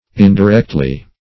Indirectly \In`di*rect"ly\, adv.